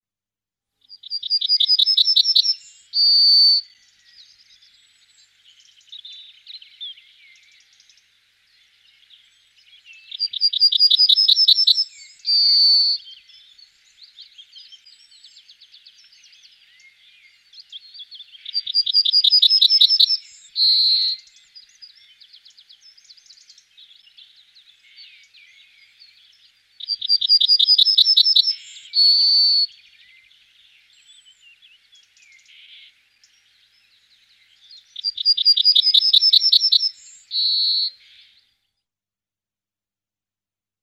Chant :
Bruant jaune
Chant monotone qu'il émet généralement perché : 6 notes aiguës suivies d'une note finale mélancolique : tsi tsi tsi tsi tsi tsi - tiu. Cri métallique tzit ou des tzit - ut plus fluides.
96Yellowhammer.mp3